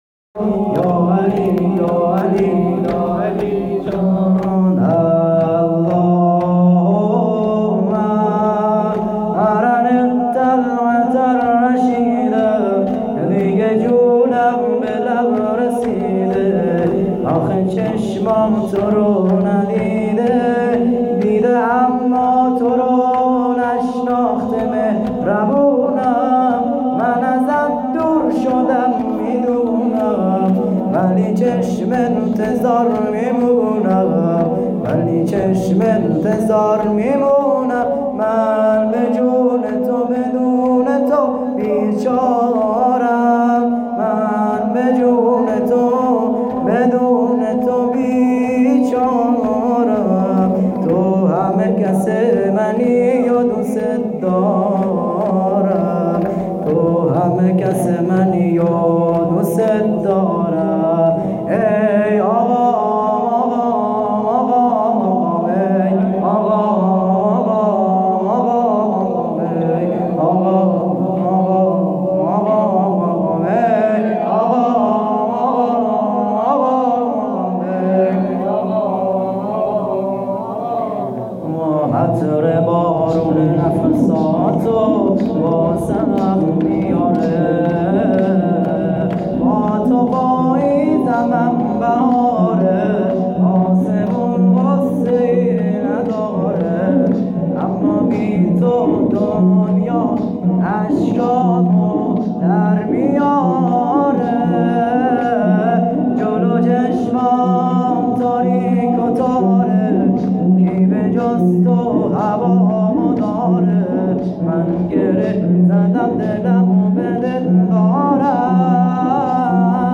مداحی رمضان۹۸